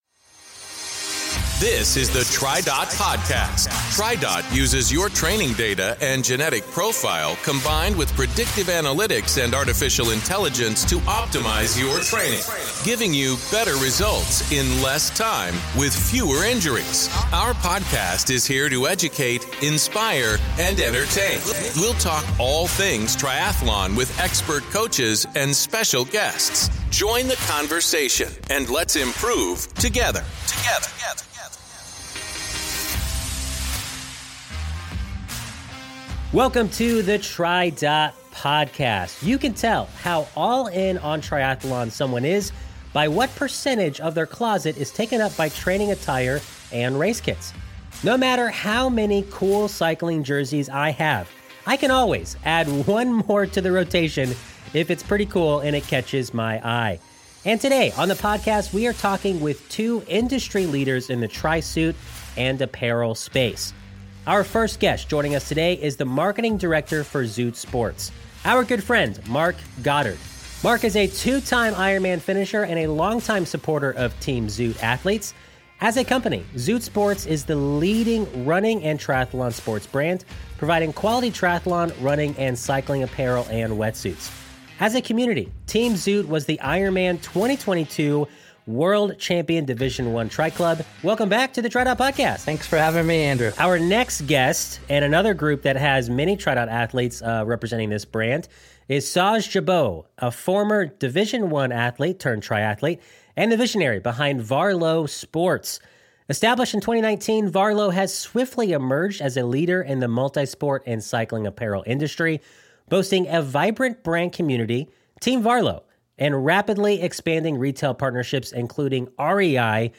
We'll talk all things triathlon with expert coaches and special guests.